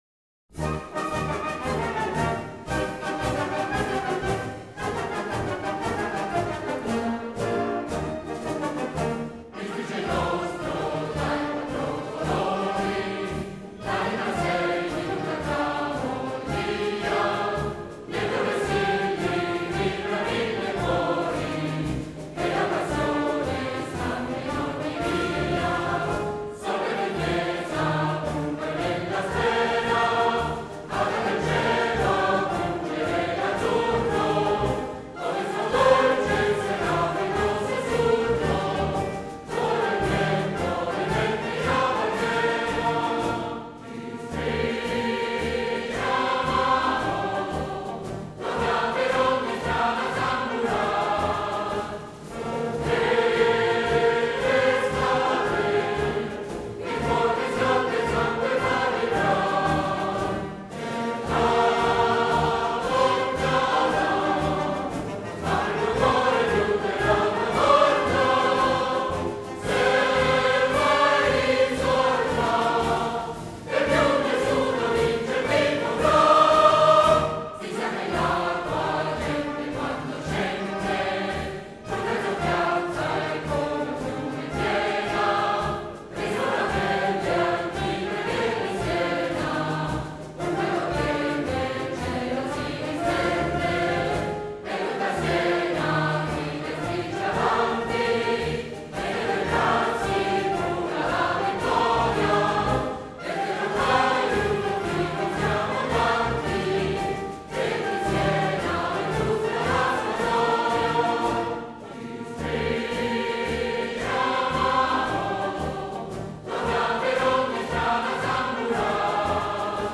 Versi di Luciano Fini, musica del Maestro Carlo Sottili – L’inno è un 6/8, molto melodico, con il coro alternativamente a una e a due voci.